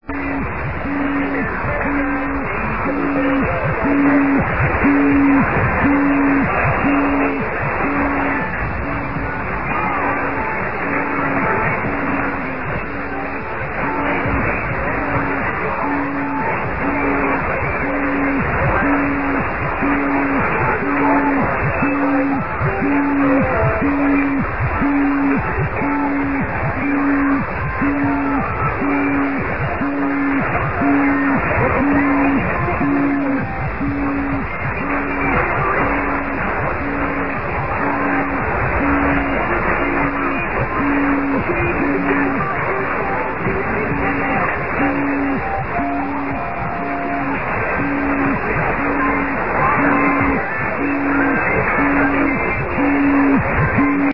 > > I have a really weird signal on 1640 here this evening at 1900 EST -
> > there is a beep once per second, but it has a strange warbly or "pumpy"
> > quality to it.
BTW, it's only audible on LSB.